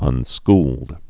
(ŭn-skld)